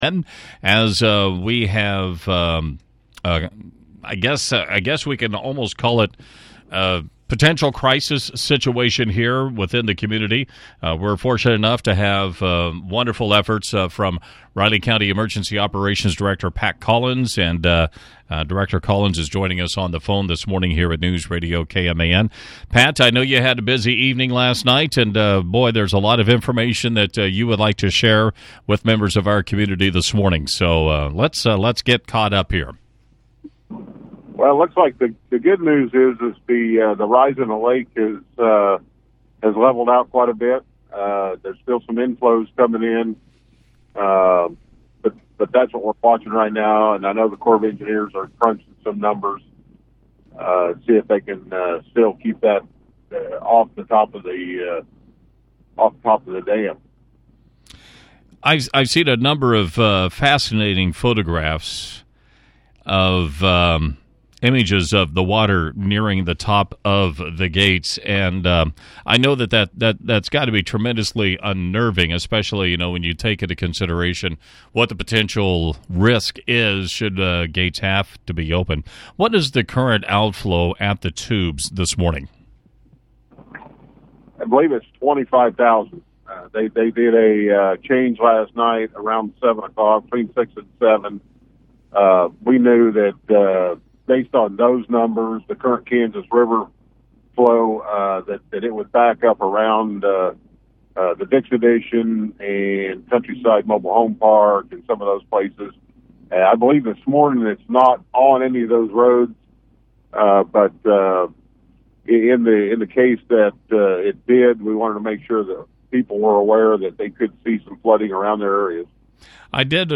Riley County Emergency Management Director Pat Collins called in to KMAN Thursday morning to give an update on conditions out at the lake. The full interview can be heard below.